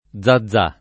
vai all'elenco alfabetico delle voci ingrandisci il carattere 100% rimpicciolisci il carattere stampa invia tramite posta elettronica codividi su Facebook Zaza [fr. @ a @# ] soprann. f. — italianizz. Zazà [ +z a zz#+ ]